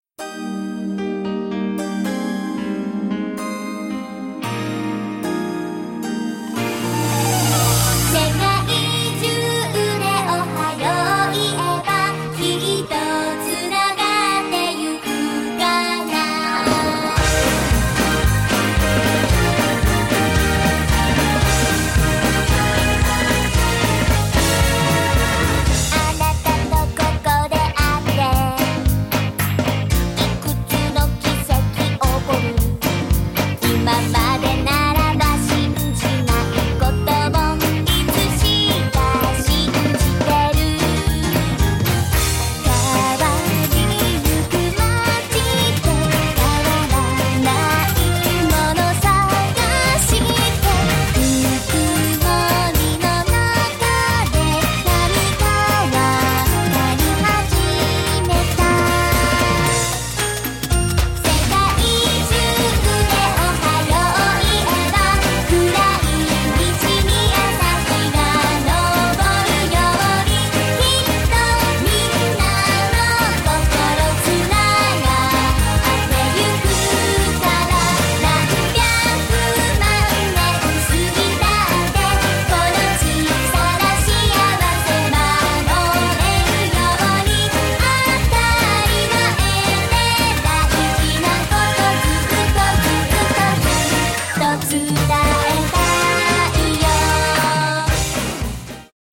reduced by -6dB